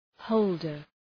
Προφορά
{‘həʋldər}